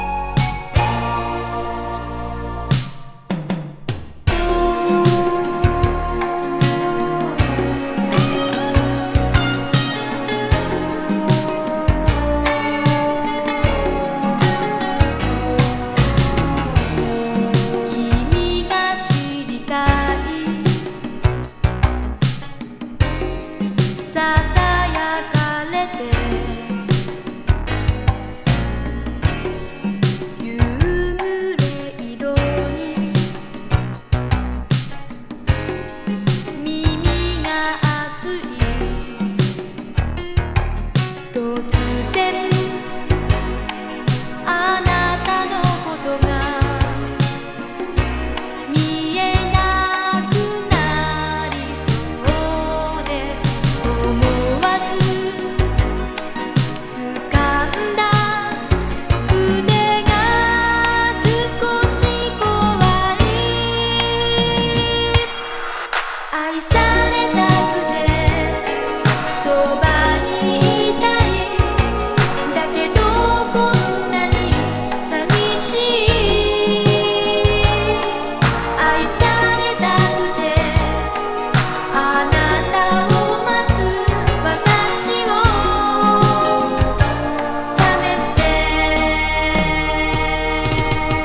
スロウAORダンサー